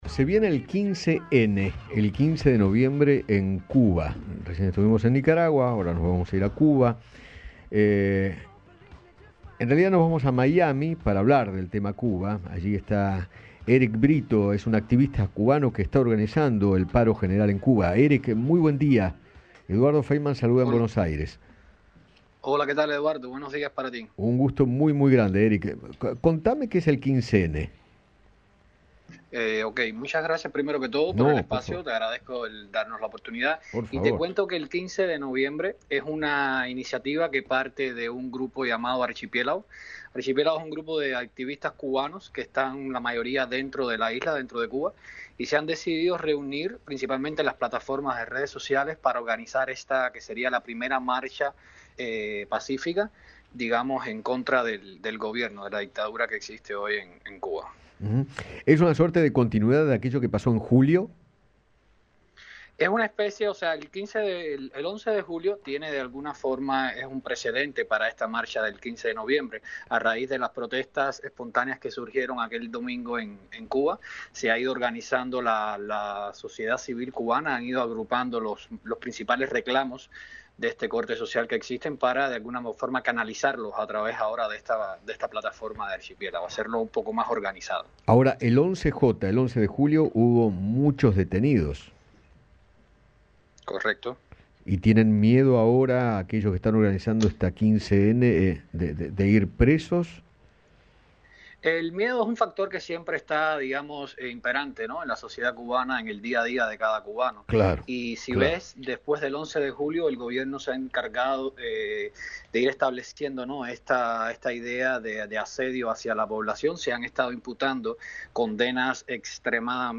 El activista cubano